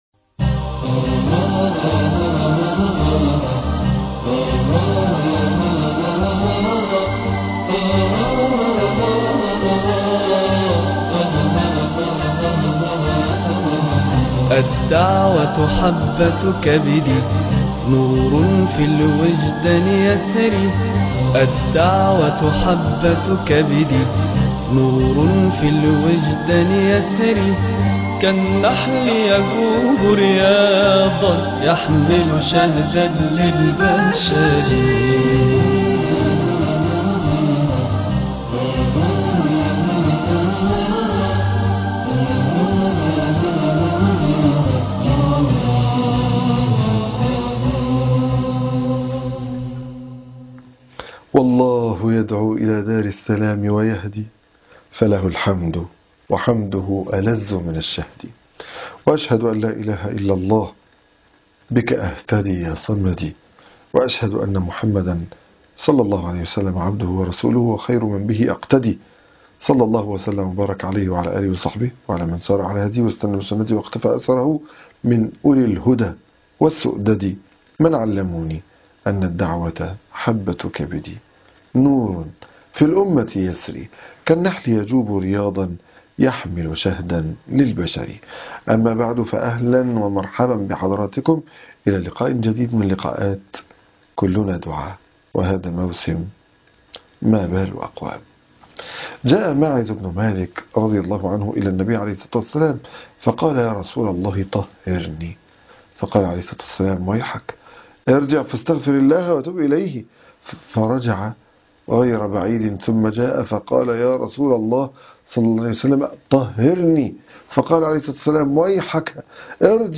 مُسرَّعة